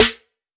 • Shiny Acoustic Snare Sample A Key 81.wav
Royality free snare drum sample tuned to the A note. Loudest frequency: 3069Hz
shiny-acoustic-snare-sample-a-key-81-LQS.wav